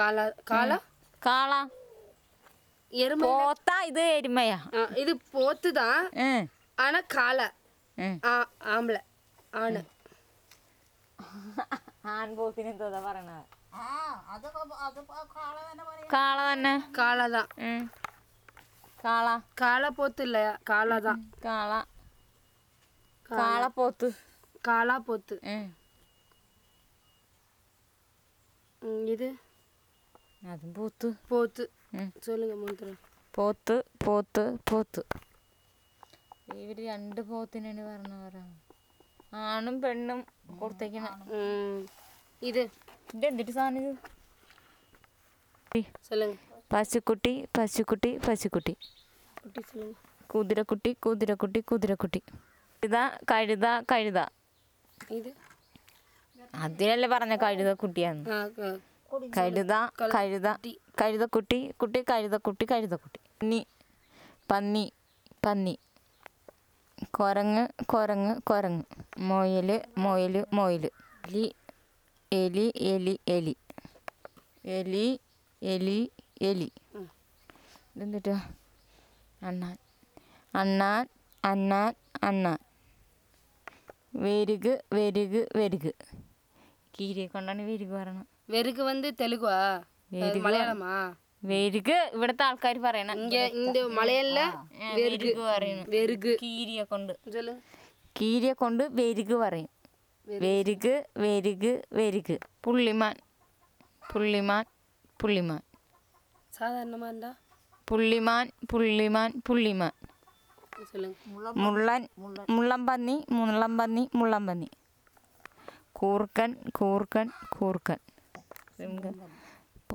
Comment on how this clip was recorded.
NotesThis is an elicitation of a words about about domestic animals, birds, wild animals, reptiles, colours, rodents and related. The data was collected by using Pictorial Glossary in Pavri Bhili published by CIIL and Bhasha Research & Publication Center.